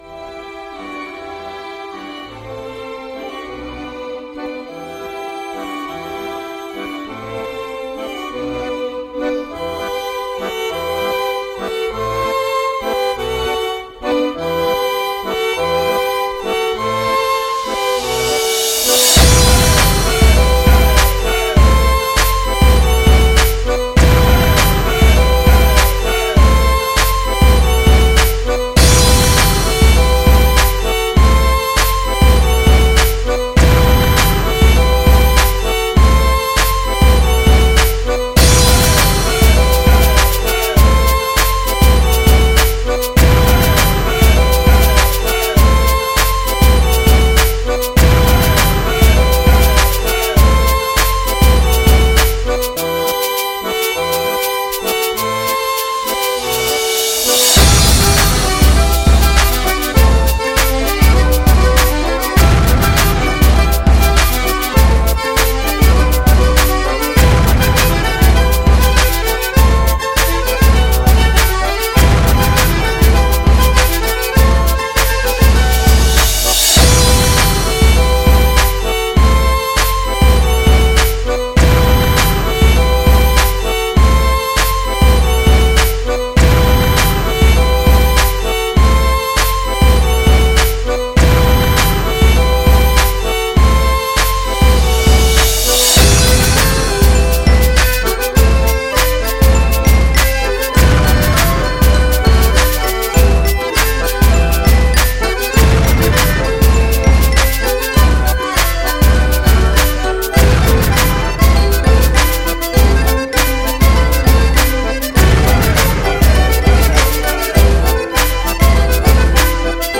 Классика-в-современной-обработке-–-скрипка-и-бит.mp3